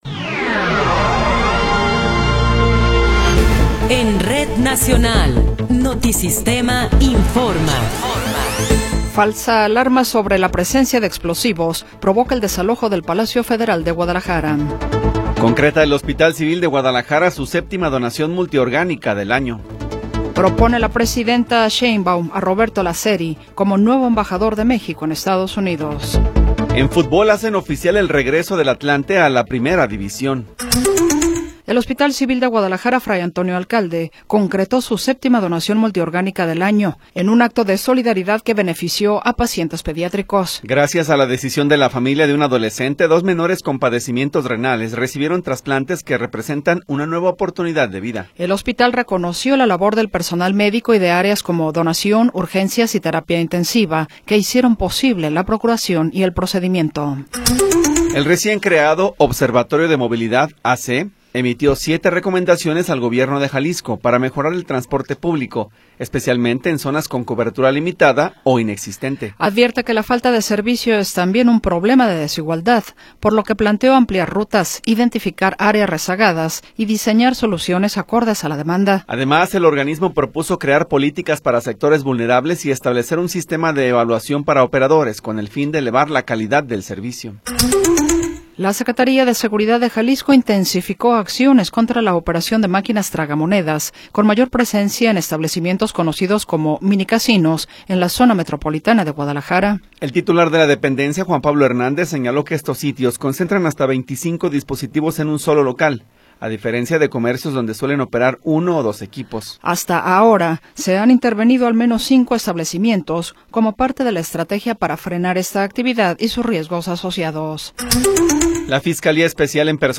Noticiero 14 hrs. – 23 de Abril de 2026
Resumen informativo Notisistema, la mejor y más completa información cada hora en la hora.